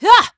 Isolet-Vox_Attack1.wav